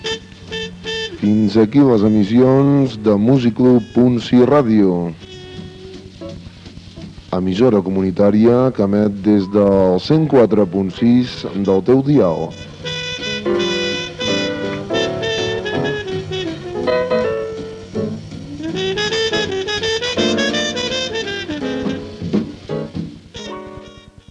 Tancament de l'emissió